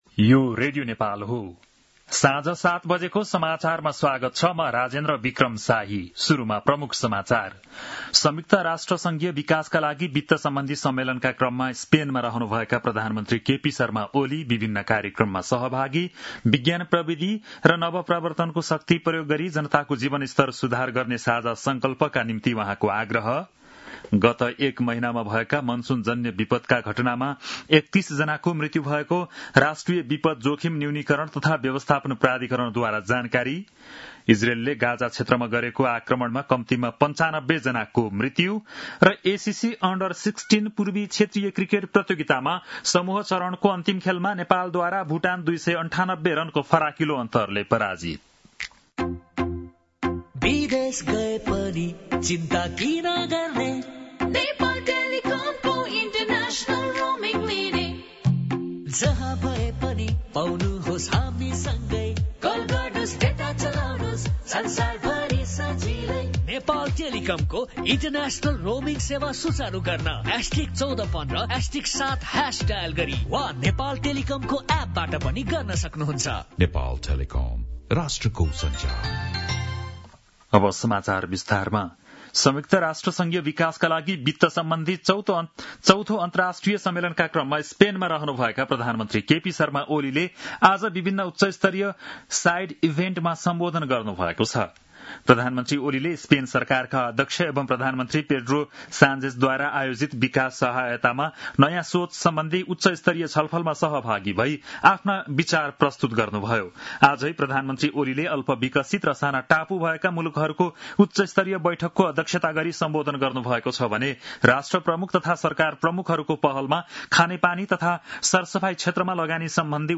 बेलुकी ७ बजेको नेपाली समाचार : १७ असार , २०८२